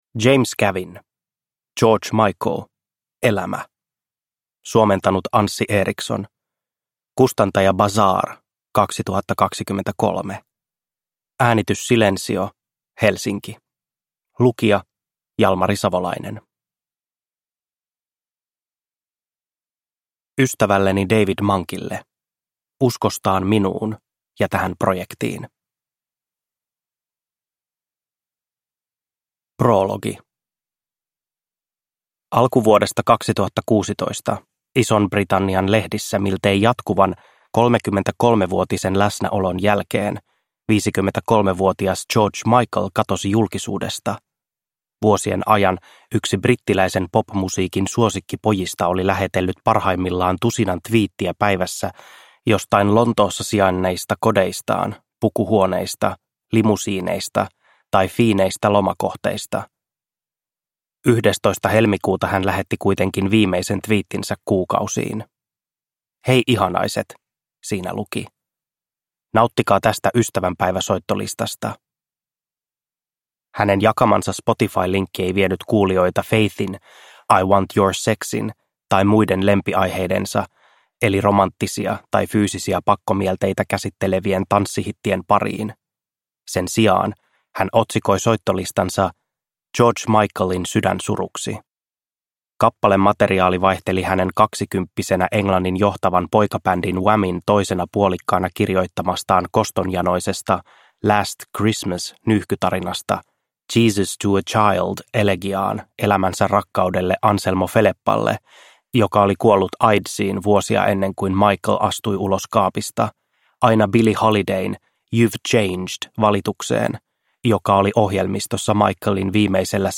George Michael: Elämä – Ljudbok